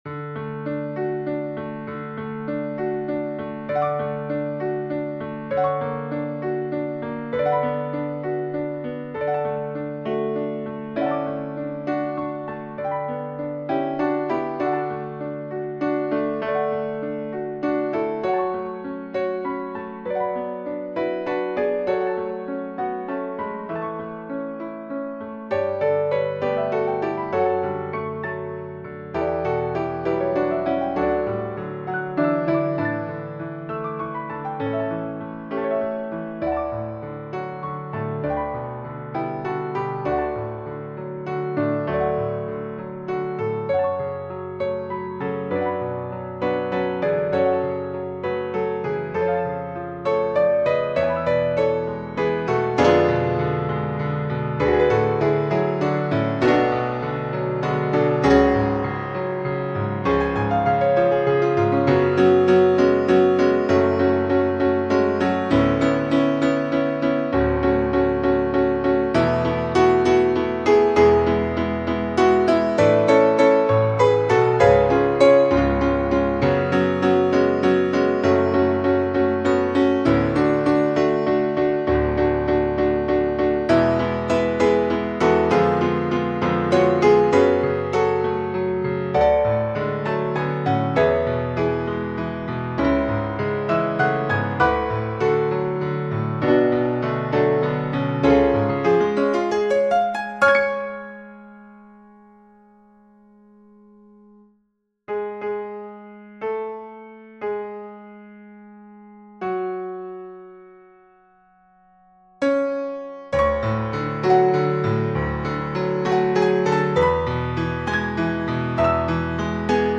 SAB mixed choir and piano